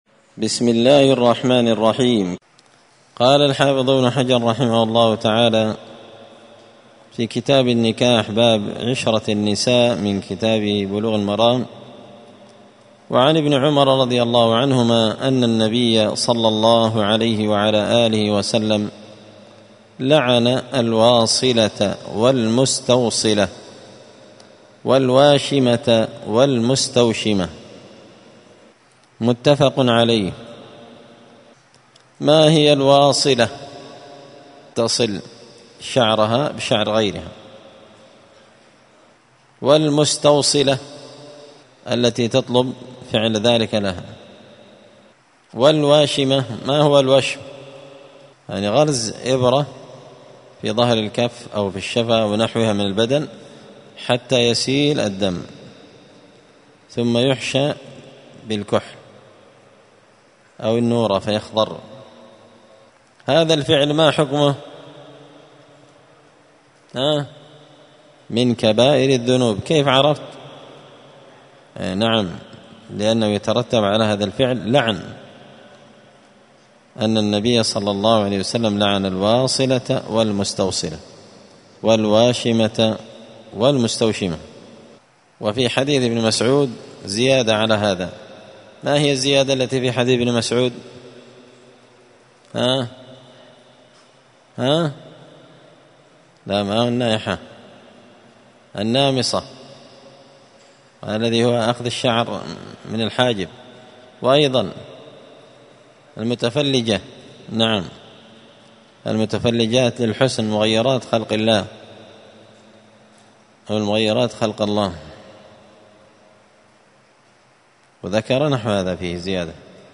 *الدرس 23 تابع أحكام النكاح {باب عشرة النساء}*